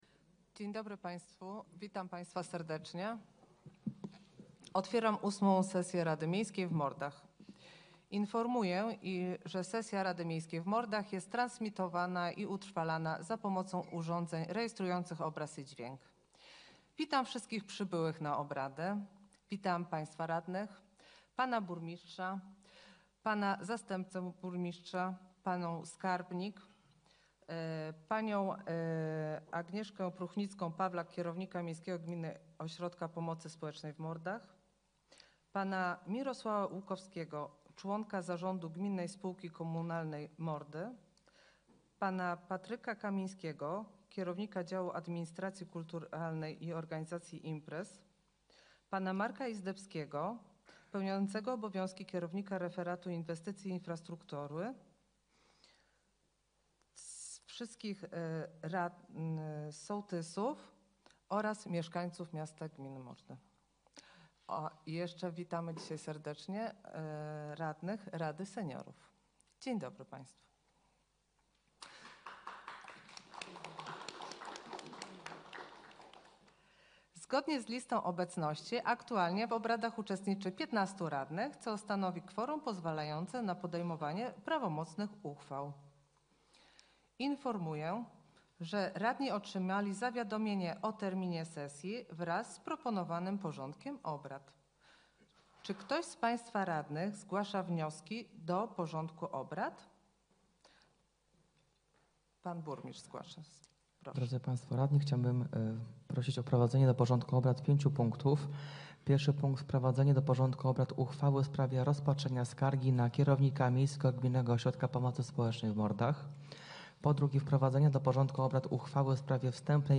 Sesja Rady Miejskiej w Mordach – 29.11.2024